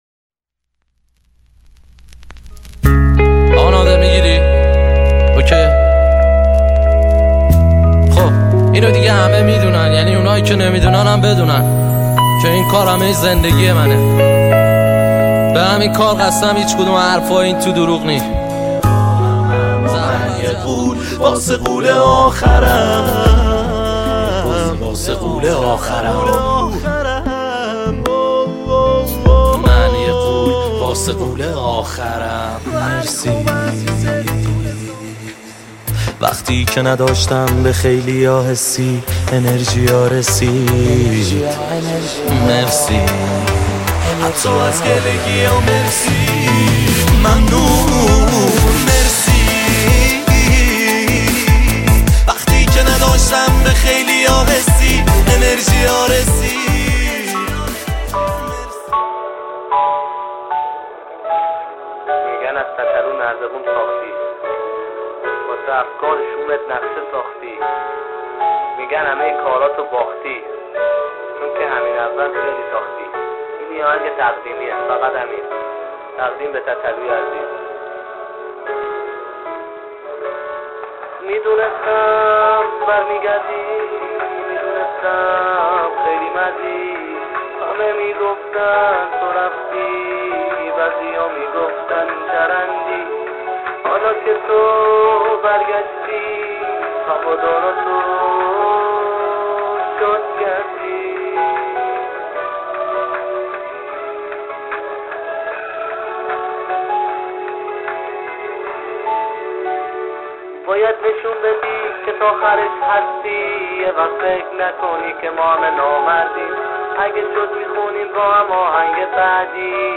این آهنگ به صورت اجرای زنده است .